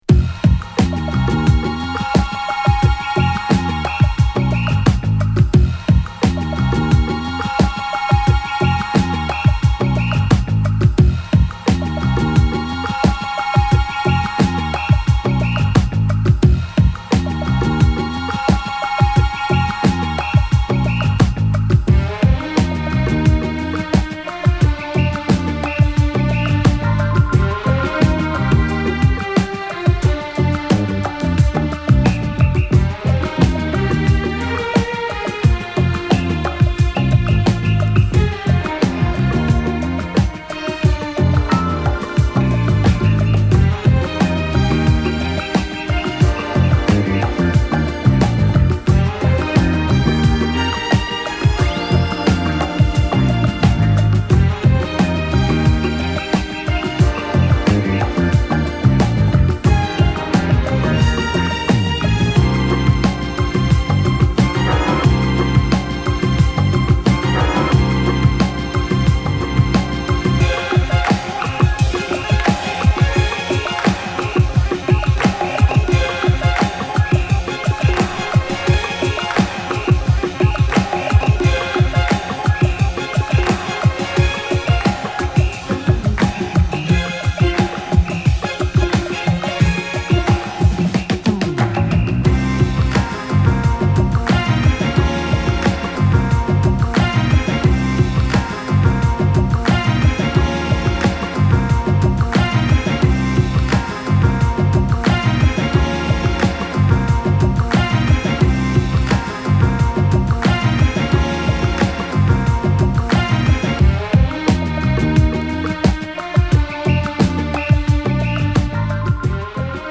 70's , Boogie
Rare Groove , Re-Edit